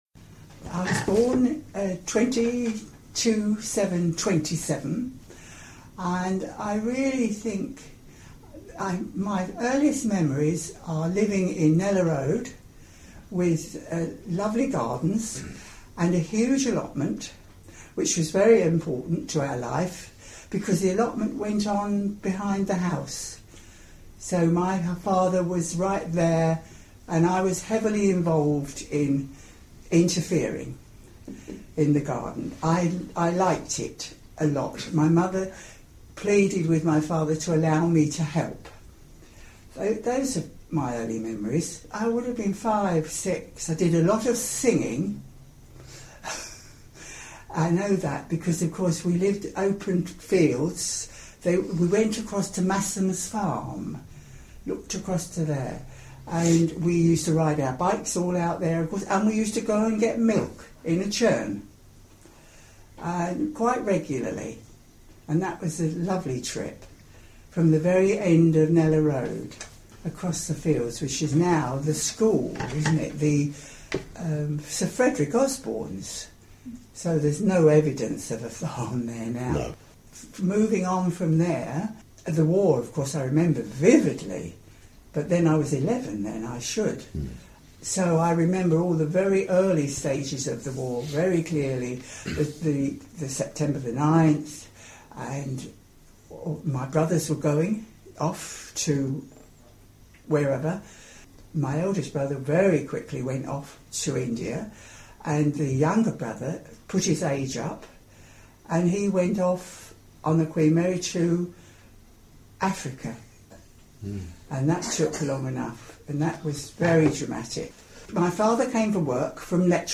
Memories - early residents contributed their recollections of the town which were digitally recorded as oral histories by a team of volunteers.